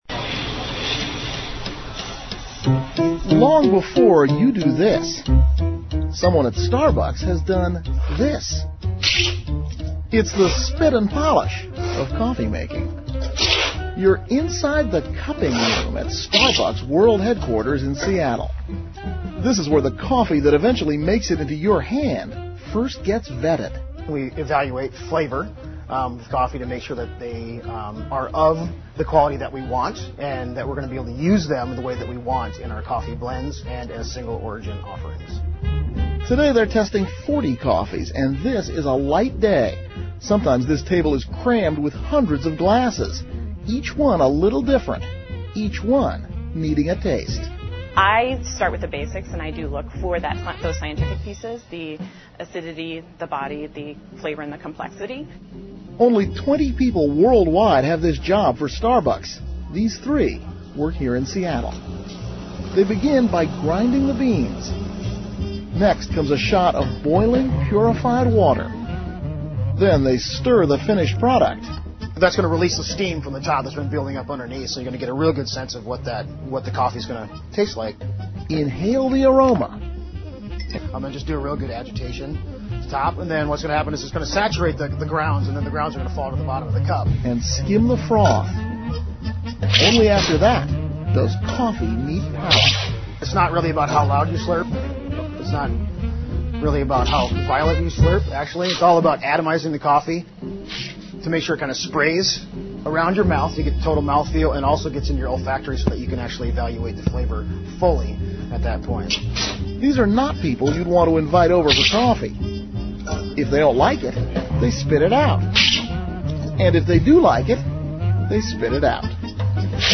访谈录 Interview 2007-04-18&20, 探讨咖啡工艺 听力文件下载—在线英语听力室